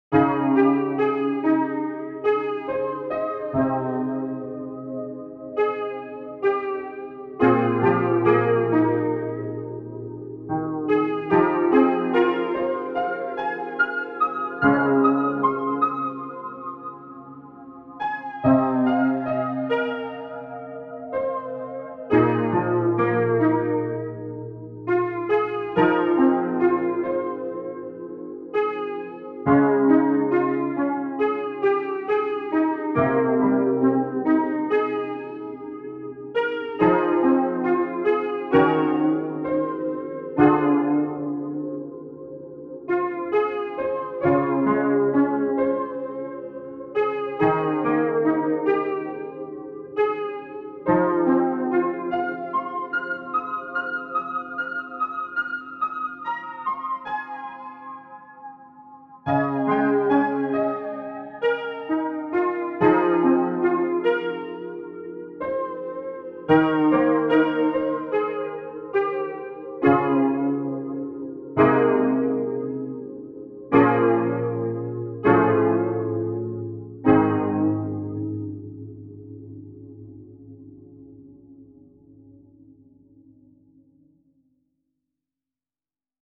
未分類 どこか懐かしい夏 幻想的 懐かしい 穏やか 音楽日記 よかったらシェアしてね！